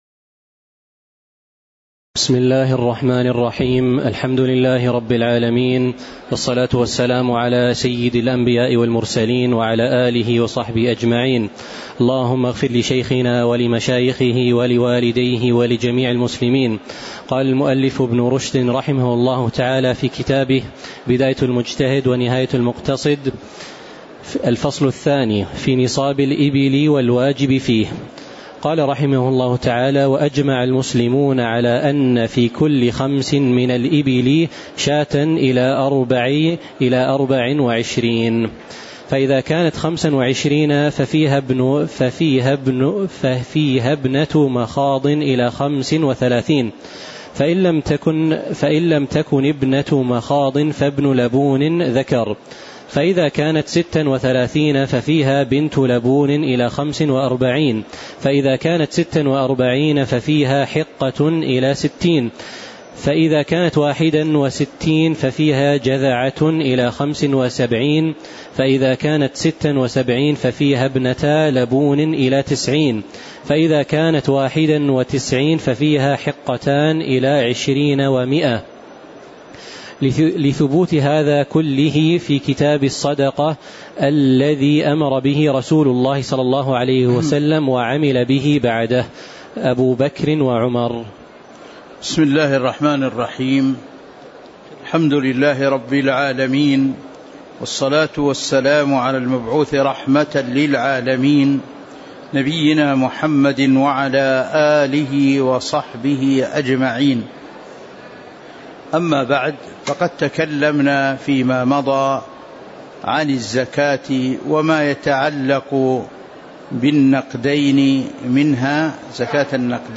تاريخ النشر ٩ رجب ١٤٤٥ هـ المكان: المسجد النبوي الشيخ